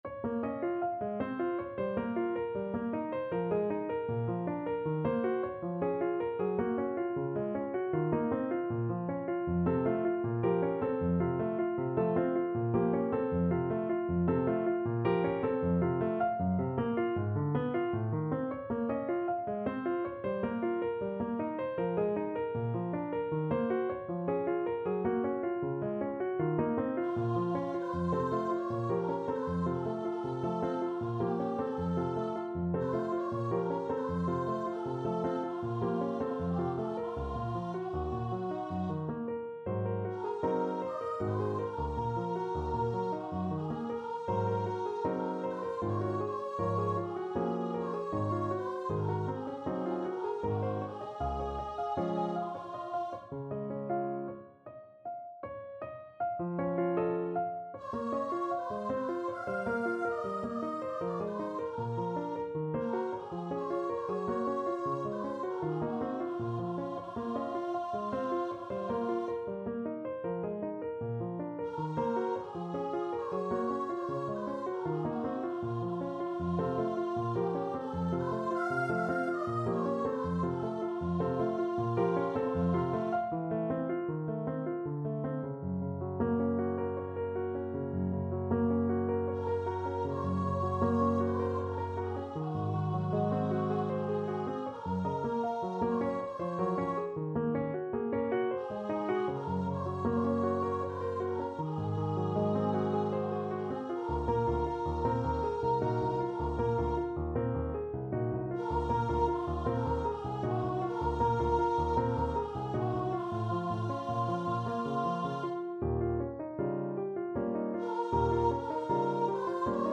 3/4 (View more 3/4 Music)
Voice  (View more Intermediate Voice Music)
Classical (View more Classical Voice Music)